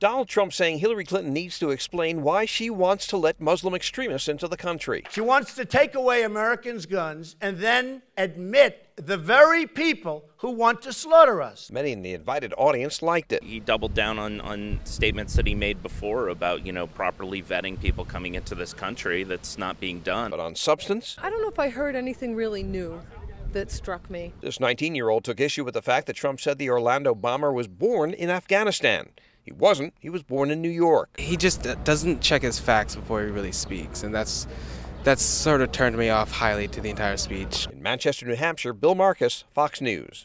Donald Trump, speaking Monday at St. Anselm College, Manchester, NH (Courtesy of AP)